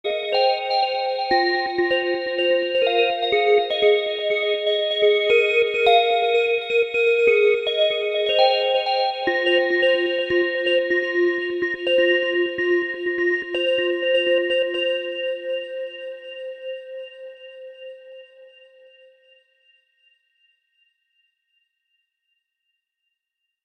digital-bells_24749.mp3